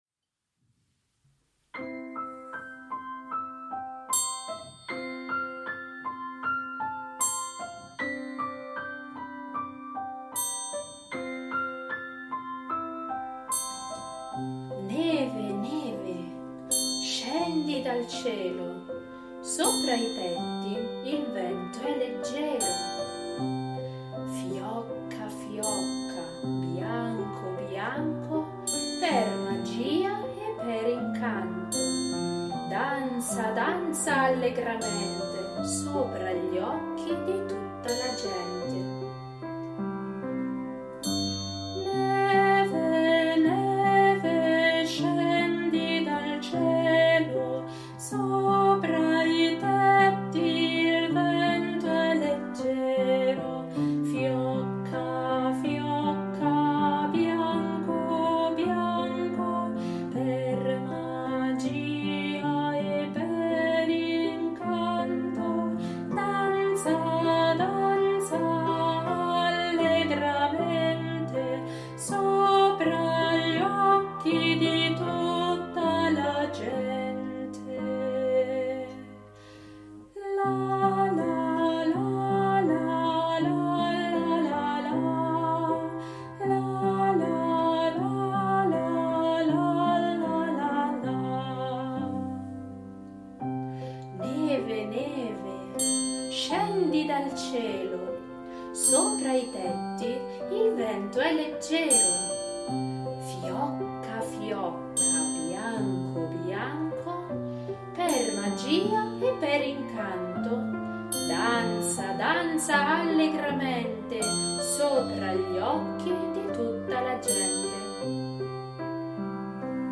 coro di voci bianche